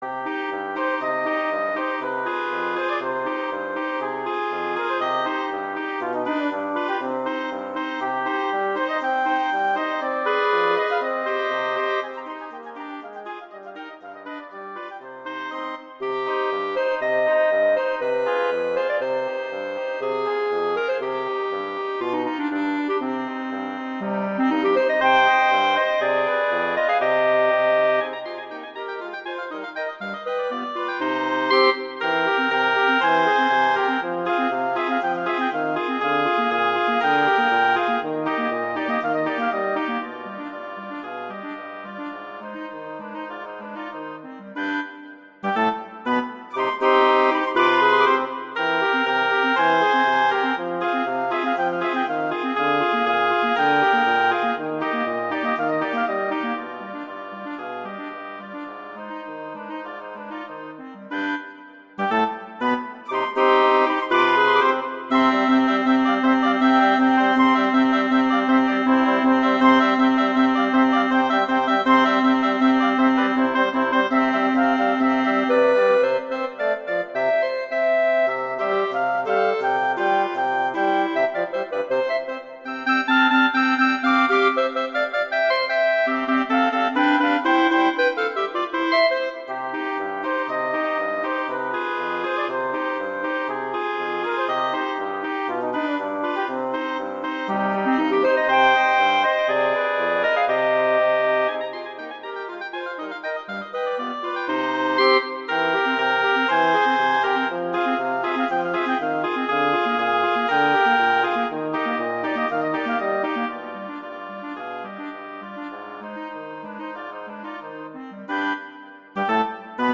arrangements for wind quartet
classical, halloween, holiday, french
flute:
oboe:
clarinet in Bb:
bassoon: